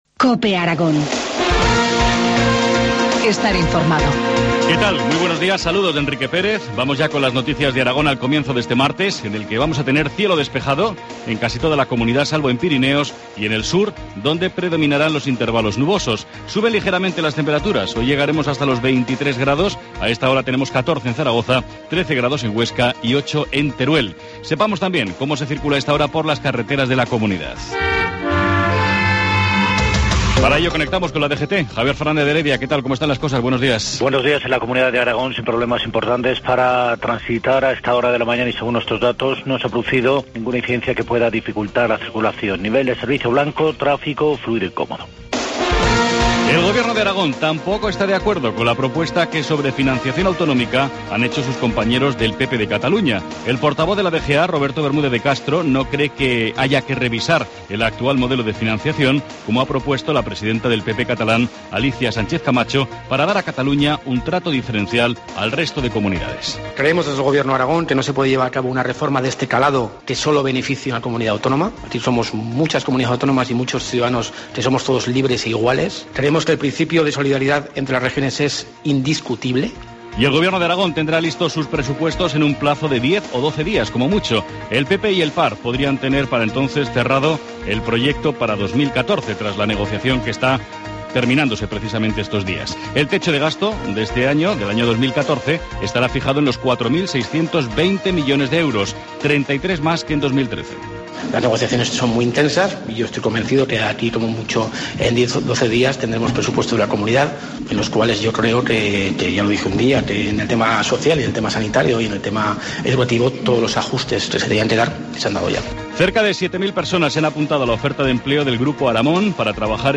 Informativo matinal, martes 8 de octubre, 7.25 horas